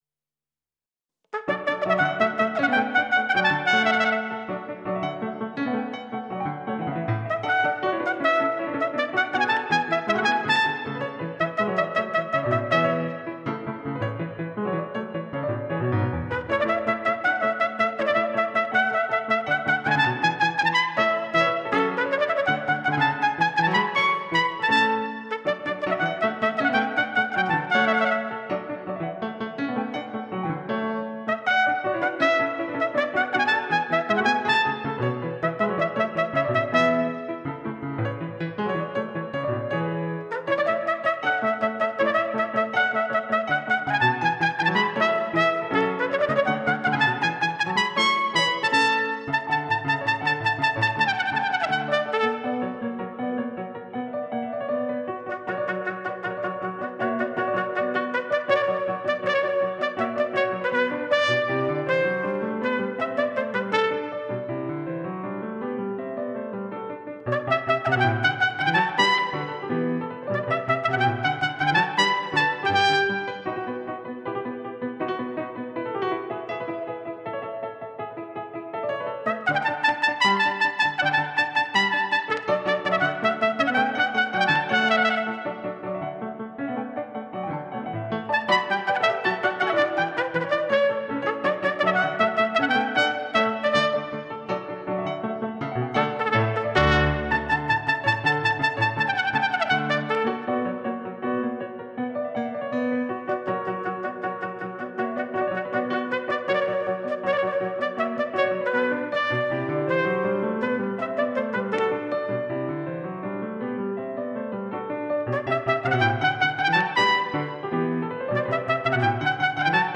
trumpeter